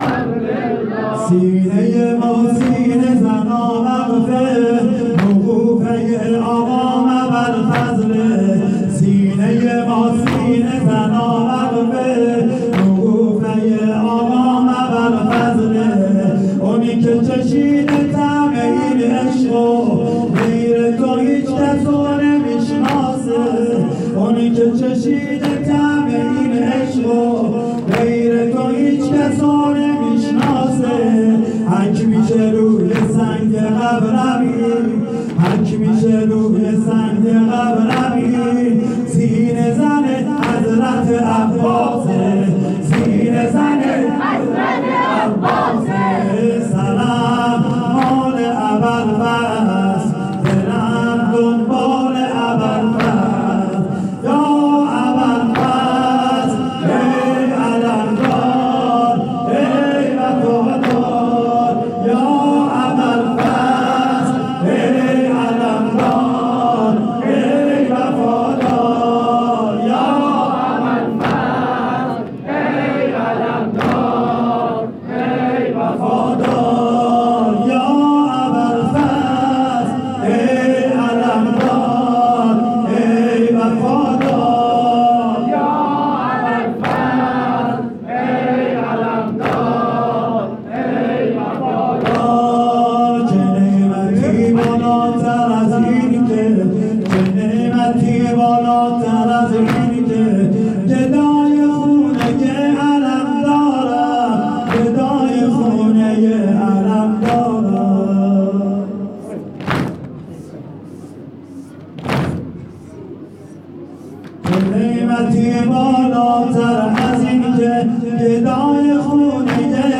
دانلودفایلهای صوتی شب چهارم محرم96
2-سنگین-سینه-ما-سینه-زنا-وقف.mp3